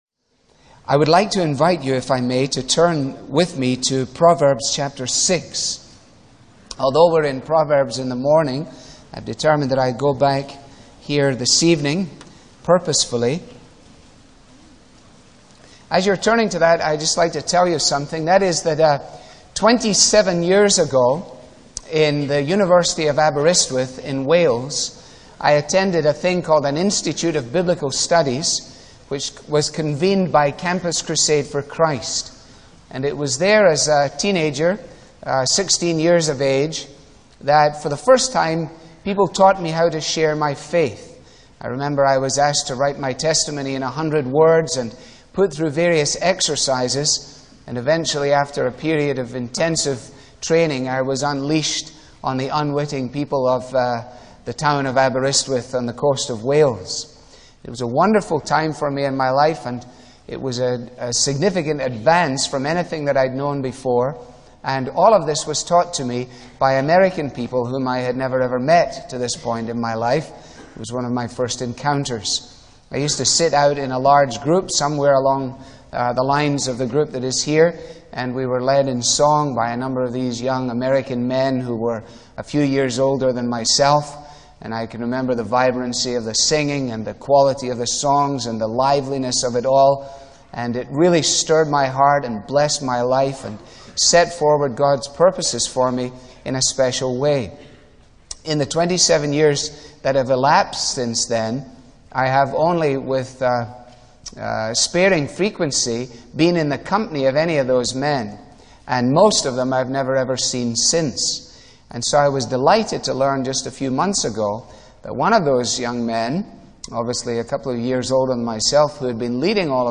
Pastor Alistair Begg was the speaker for the 1995 Fall Bible Conference. His message is taken from Proverbs 6.